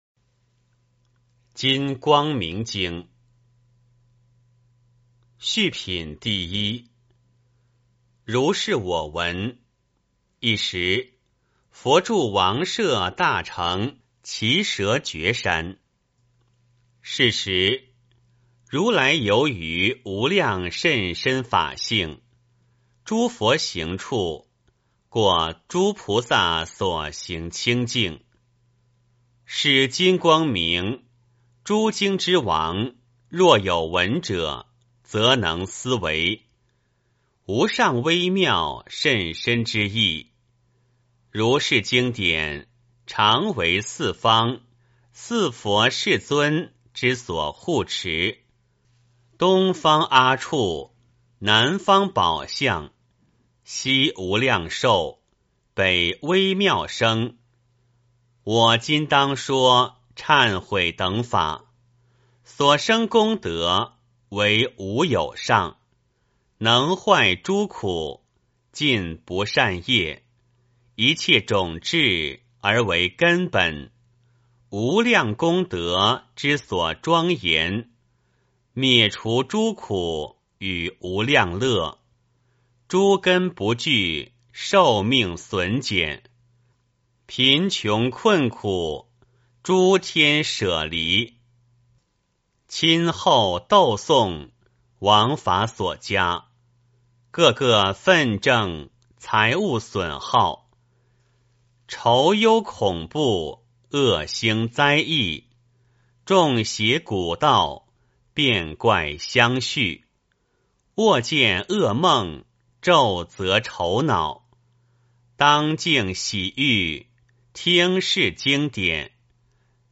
金光明经-01-念诵